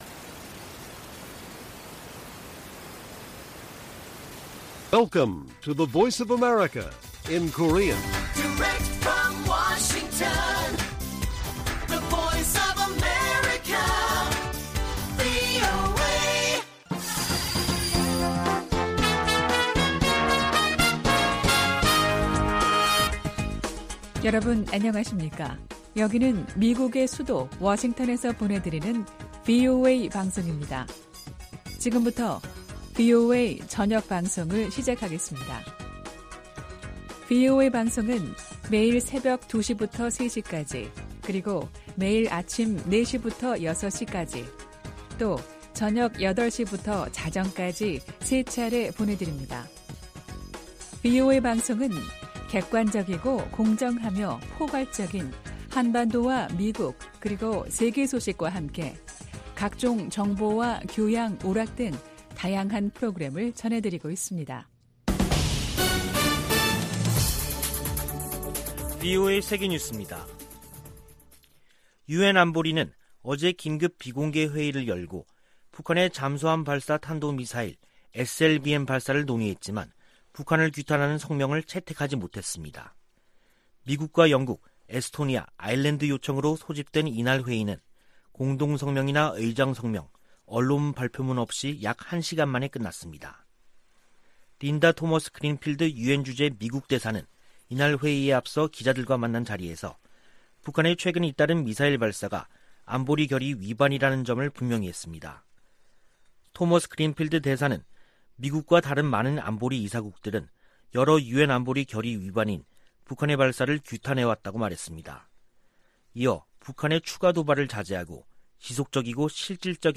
VOA 한국어 간판 뉴스 프로그램 '뉴스 투데이', 2021년 10월 21일 1부 방송입니다. 유엔 안보리가 북한 SLBM 규탄 성명을 내는데 실패했습니다. 독일과 영국이 북한의 미사일 시험 발사를 규탄하며, 완전하고 검증 가능하며 되돌릴 수 없는 핵포기를 촉구했습니다. 북한은 SLBM 시험발사가 미국을 겨냥한 게 아니라고 주장했습니다.